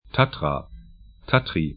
Tatra 'tatra Tatry 'tatri sk Gebirge / mountains 49°10'N, 20°08'E